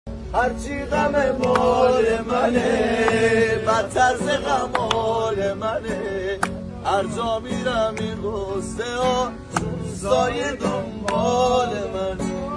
اجرای گروهی با گیتار